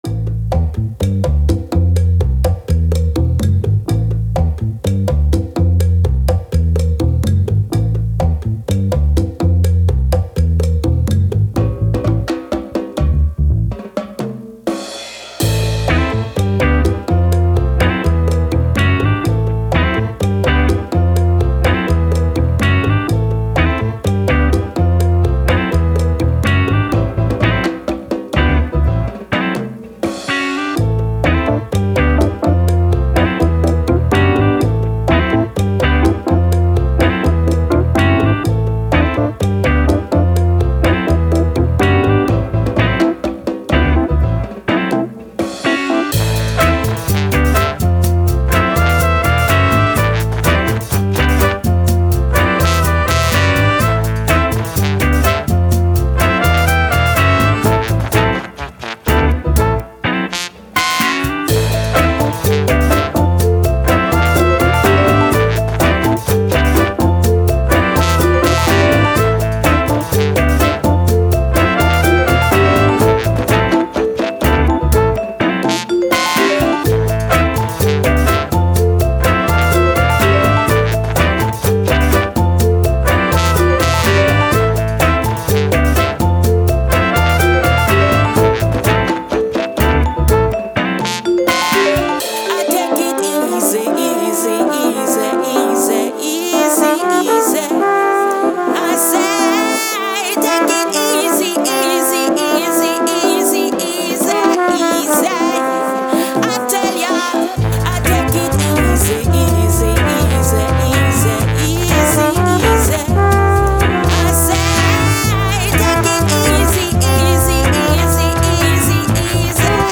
Latin, Bossa Nova, Reggae, Dub, Sun, Beach, Brass